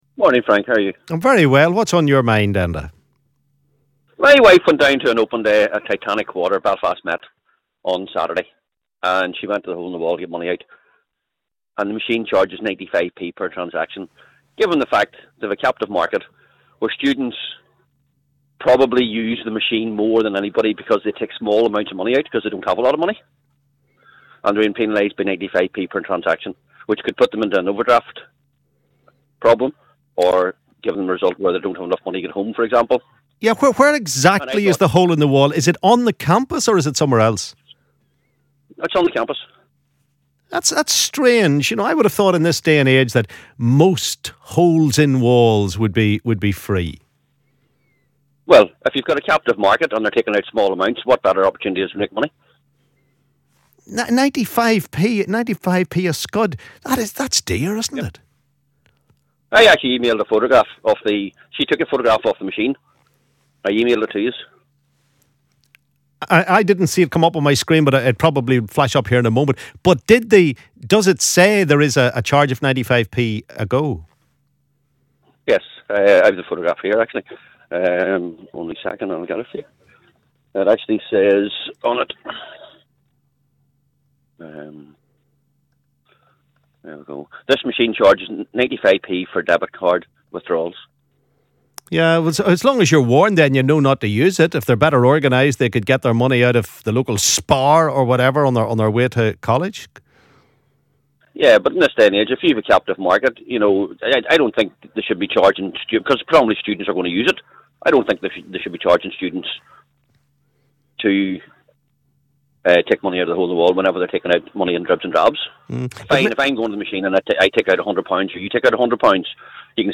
LISTEN ¦ Caller takes issue with cash machine which charges for transactions